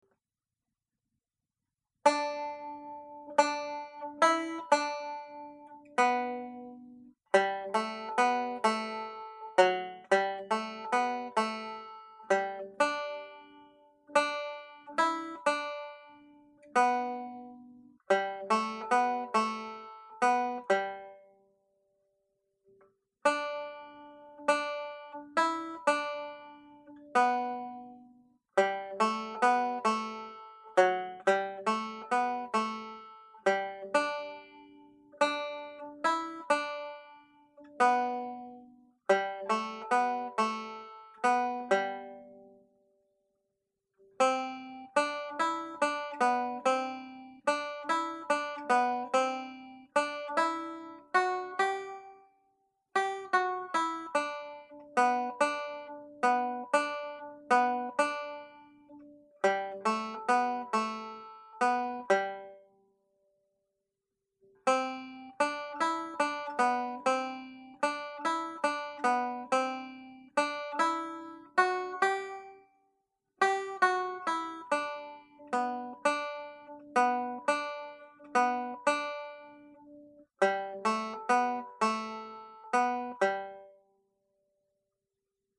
(G Major)
Tune played slowly